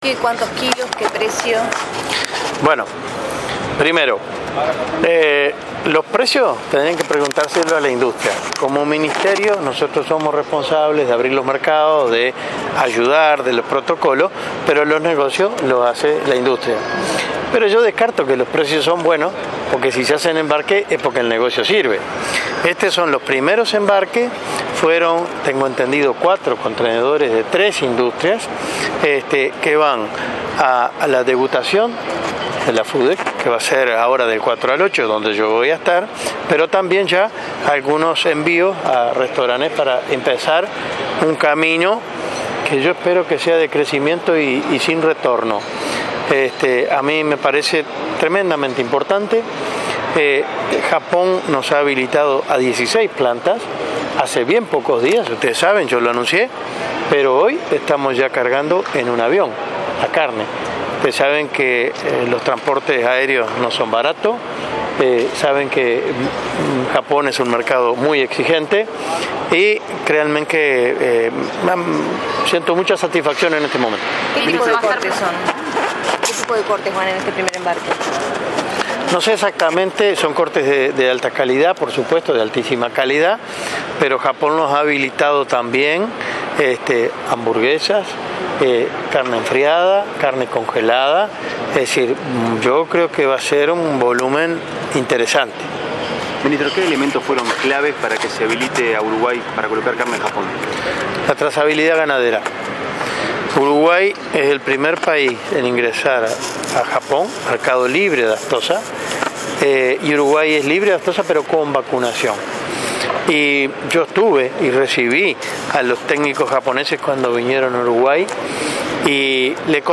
Autoridades, el sector privado y la Embajada de Japón en Uruguay brindaron una conferencia de prensa en el Aeropuerto Internacional por la primera exportación de cortes bovinos hacia el mercado japonés.
Audio Ministro Enzo Benech mp3
Entrevista